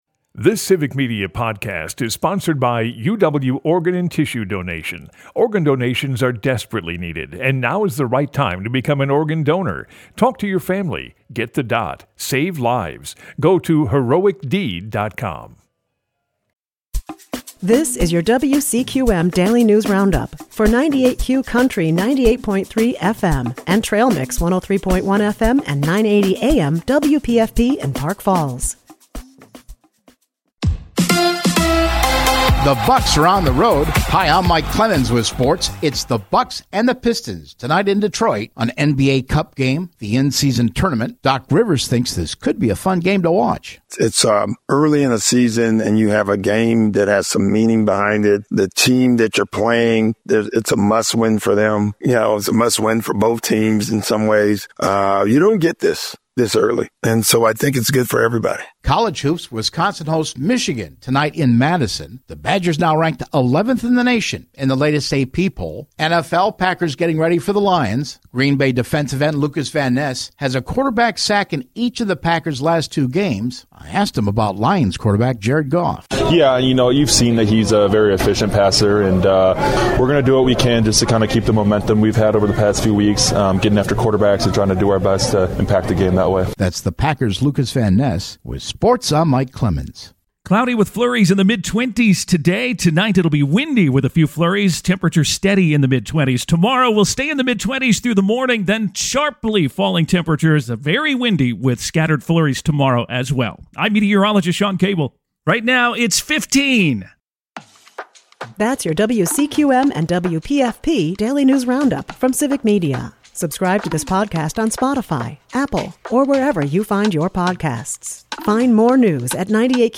98Q Country WCQM and WPFP have your state and local news, weather, and sports for Park Falls, delivered as a podcast every weekday.